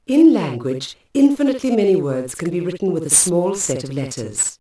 spraak in een semi-anechoïsch veld met d=10 m.
Dit fragment werd achteraf bewerkt om het geluidveld in de omgeving van een reflecterende wand en een galmveld te simuleren.
Speech_echo10m2.wav